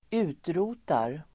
Uttal: [²'u:tro:tar]